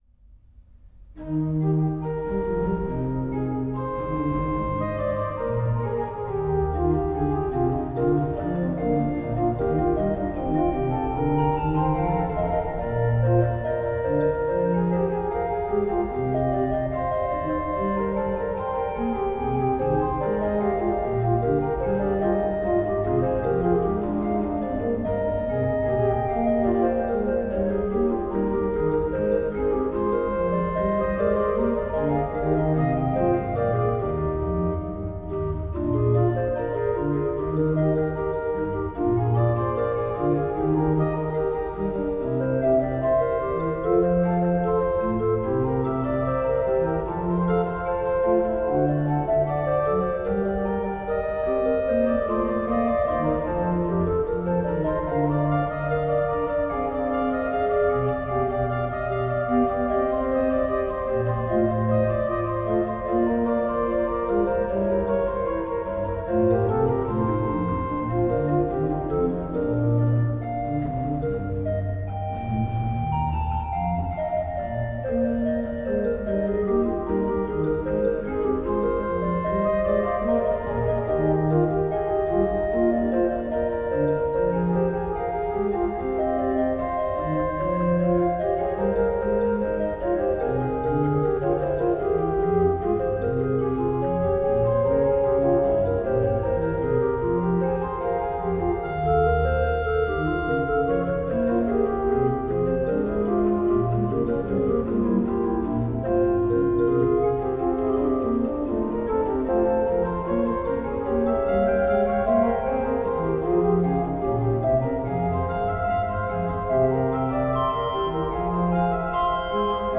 纯音雅乐
分类： 古典音乐、新世纪、纯音雅乐